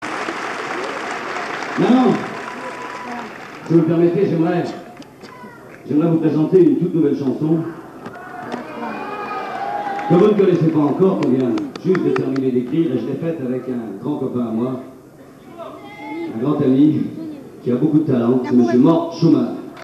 Bootlegs (enregistrements en salle)
Marignane (12 juillet 1978)